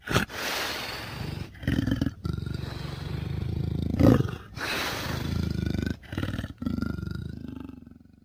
Add leopard sounds
sounds_leopard_saw_02.ogg